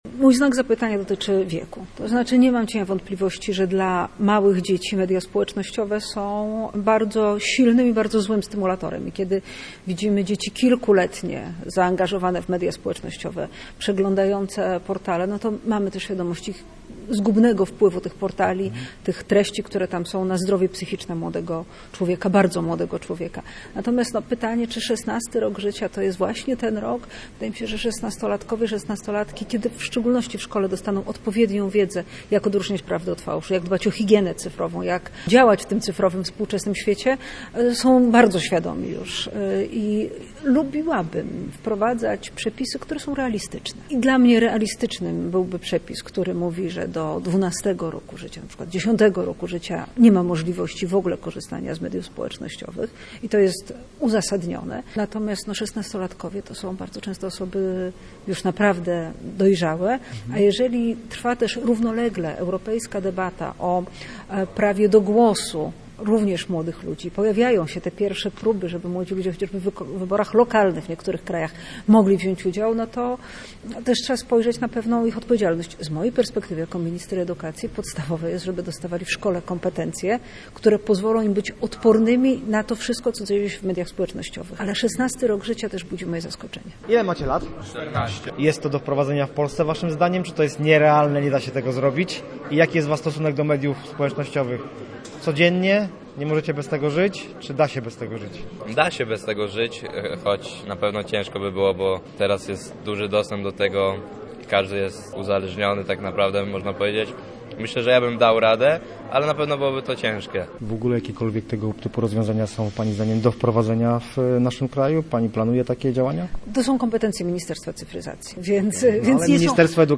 Na pewno trzeba edukować uczniów i dbać o higienę cyfrową – podkreślała podczas wizyty w Słupsku minister Barbara Nowacka.